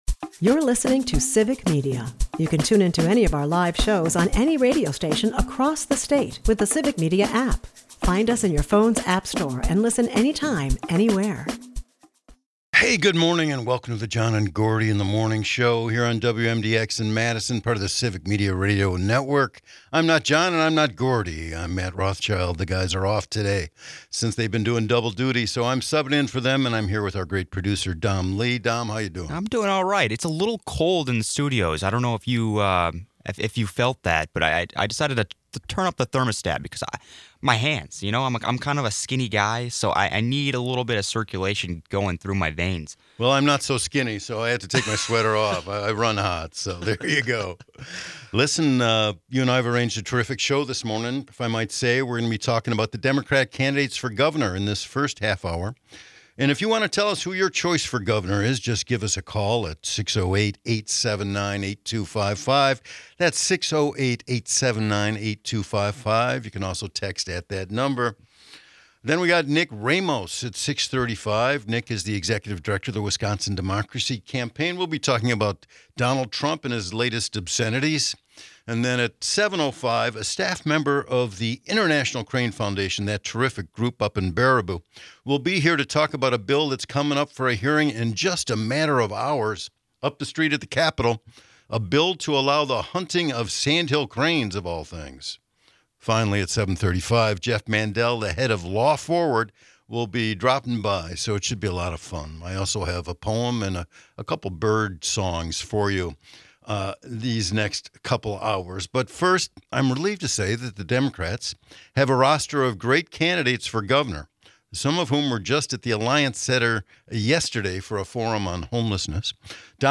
From Francesca Hong's whispery but substantial pitch to Sara Rodriguez's bold stance against Trump, each candidate brings their unique flair. County Exec David Crowley highlights the affordability crisis in education and housing.
It's a jam-packed episode with politics, poetry, and bird songs!